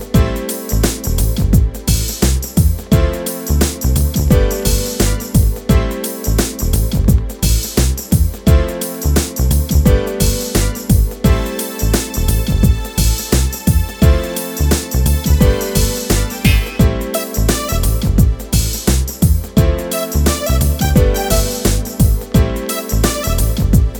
Radio Edit Duet Mix Pop (1990s) 3:49 Buy £1.50